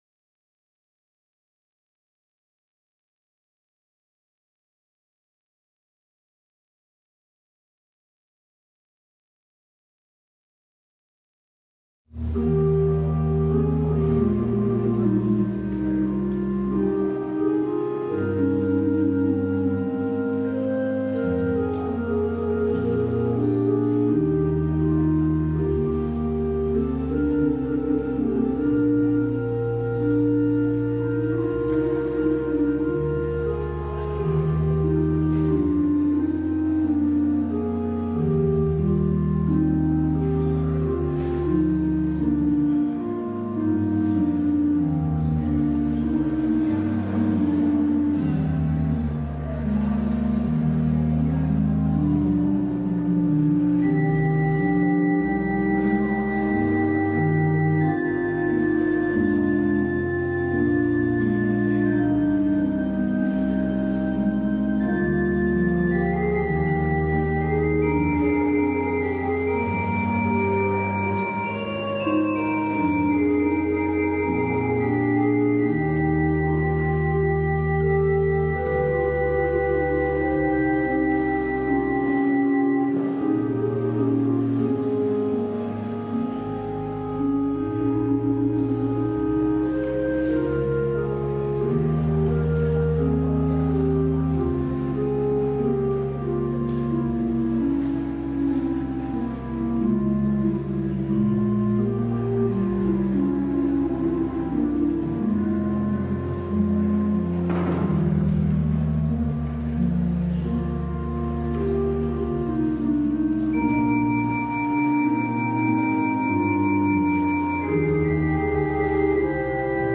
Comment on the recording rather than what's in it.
LIVE Morning Worship Service - The Imperfect Anointed: The Fool and the Furious